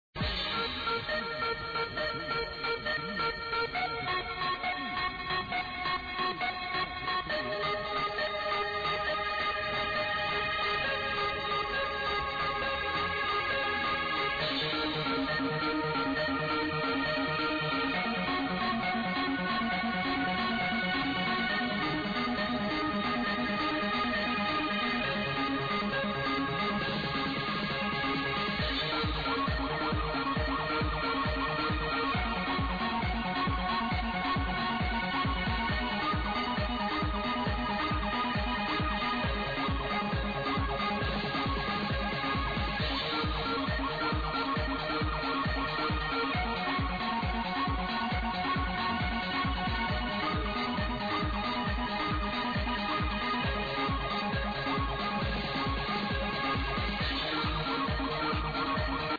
No additionnal info, except it's recorded from an afterclub in Belgium about 4 or 5 years ago.